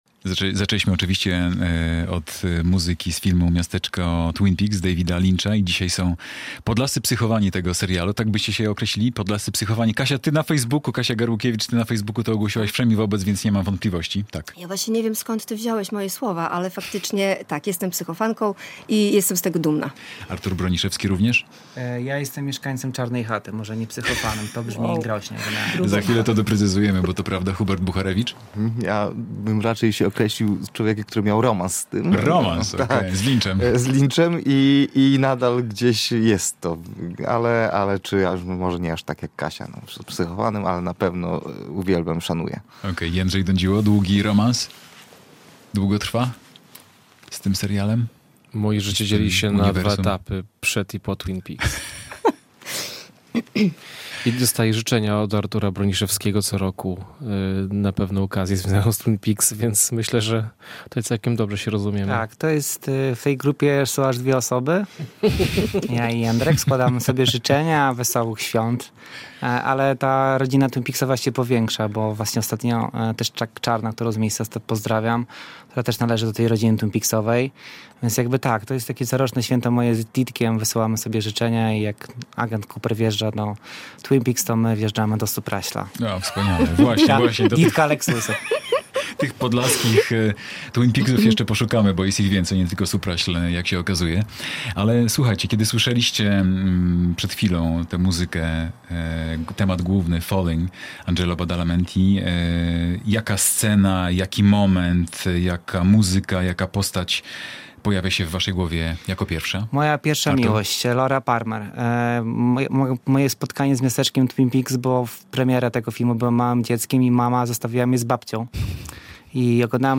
Z "psychofanami" serialu "Miasteczko Twin Peaks" rozmawia